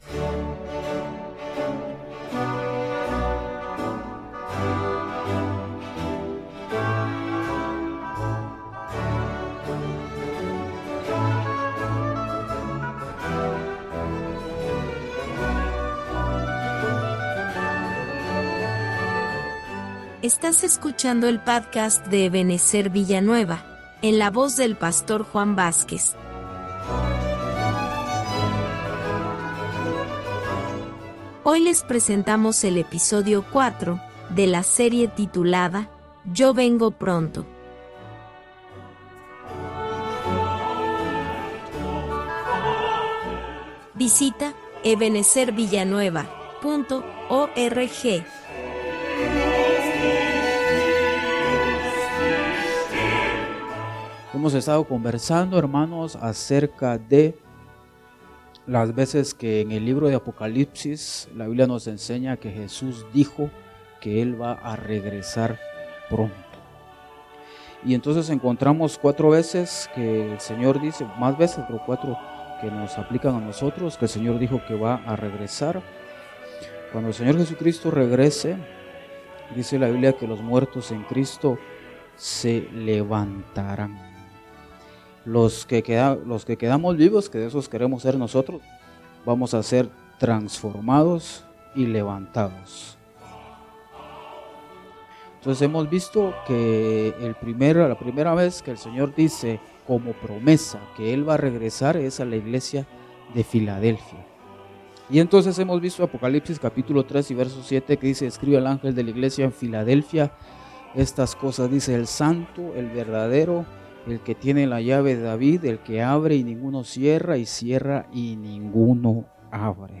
Estudio bíblico sobre el regreso de Cristo y la importancia de vencer espiritualmente según Apocalipsis.